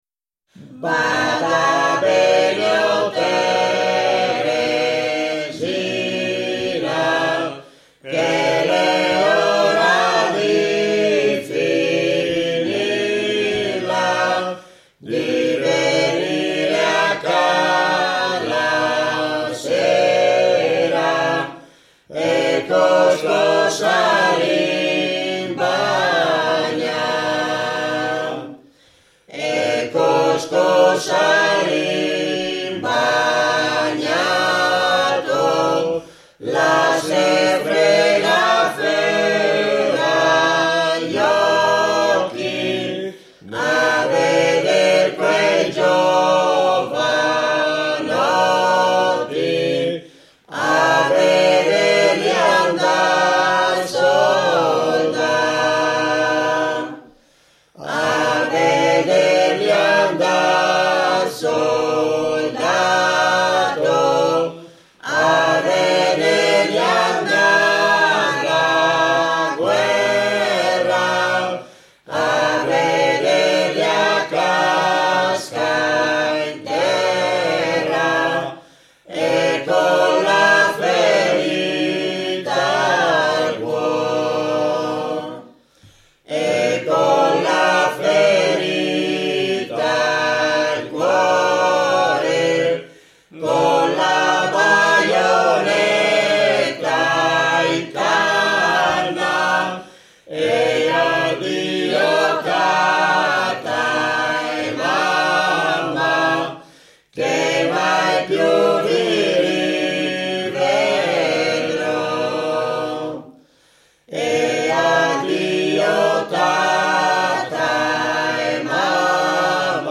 Dossena canti popolari